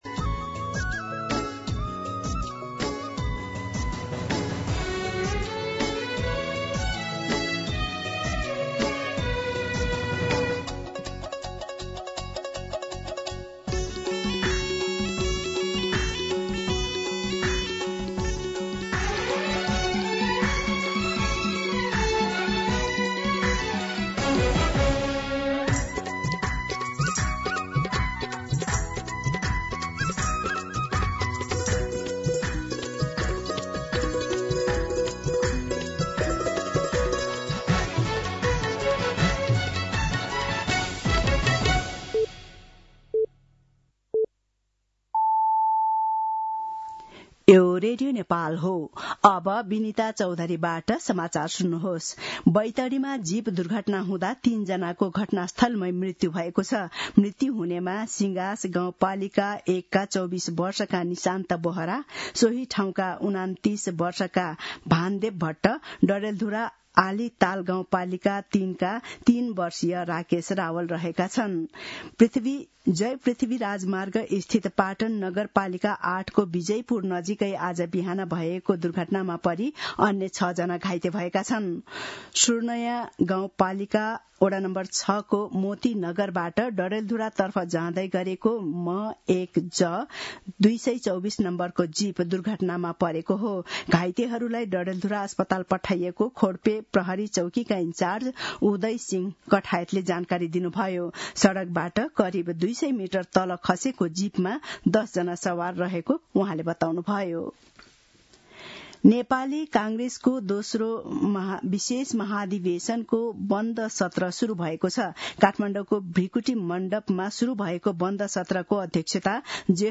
दिउँसो १ बजेको नेपाली समाचार : २८ पुष , २०८२